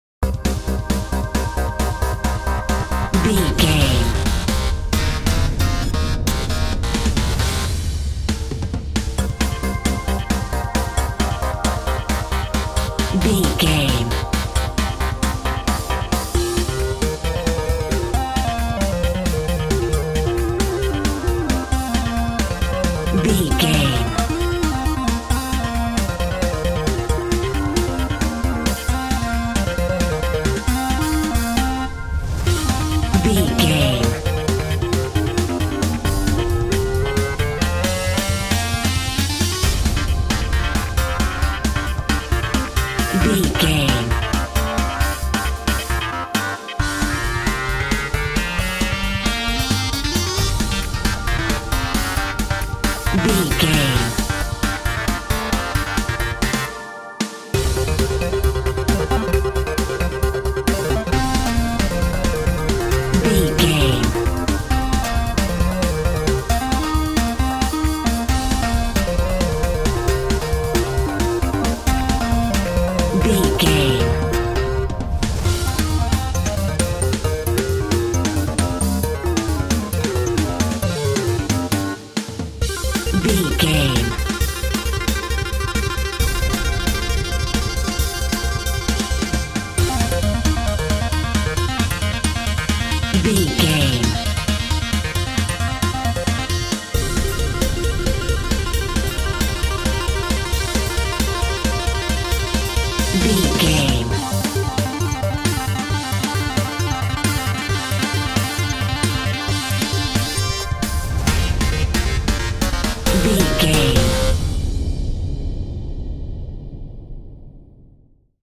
Fast paced
Aeolian/Minor
Fast
intense
8bit
aggressive
energetic
frantic
synth
drums
percussion